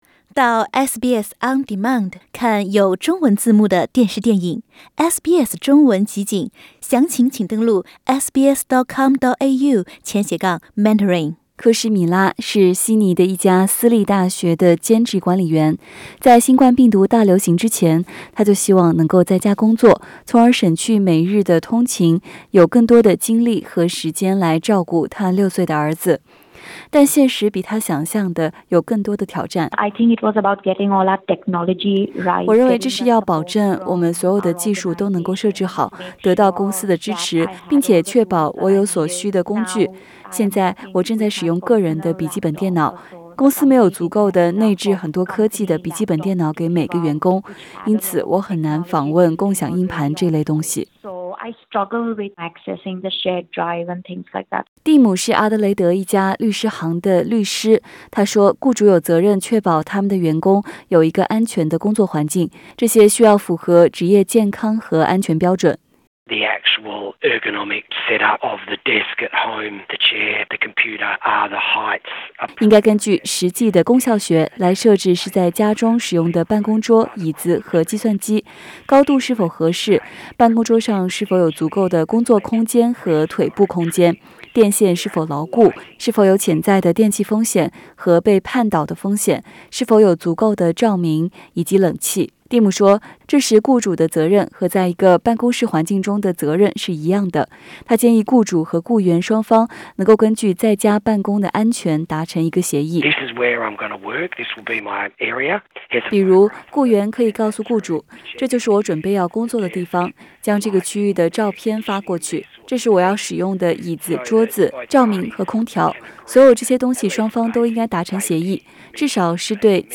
【COVID-19报道】安居澳洲：在家办公，您都有哪些权利和义务？